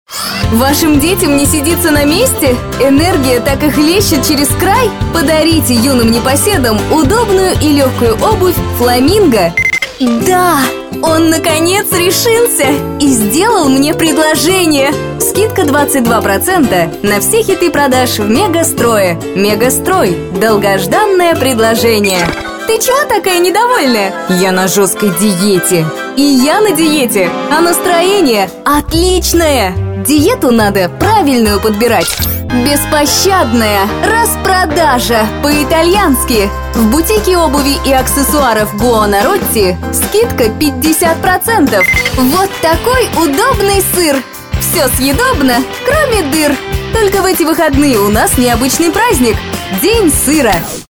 Голос универсальный, подходит как для динамичных и энергичных партий, так и для нежных чувственных начиток! Запись игровых, информационных, имиджевых роликов, IVR, презентаций.
Тракт: : Микрофон Gefell M930 Аудиоинтерфейс Babyface PRO